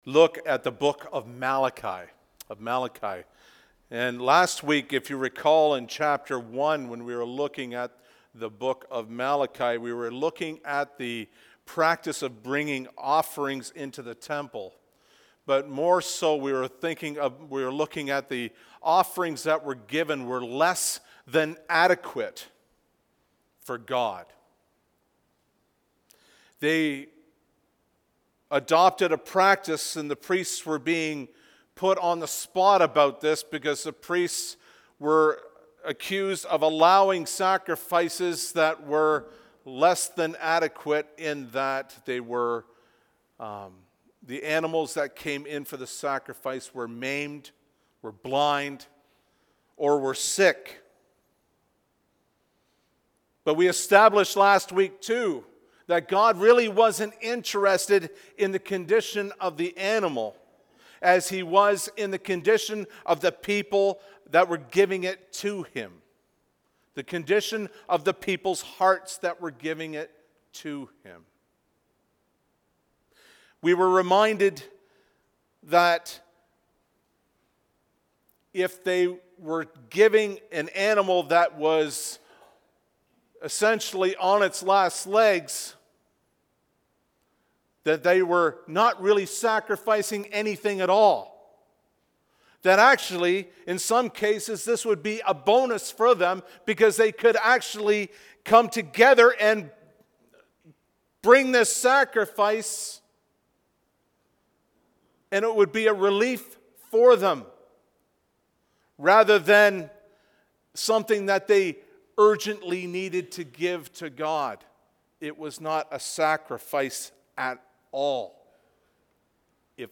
Sermon Messages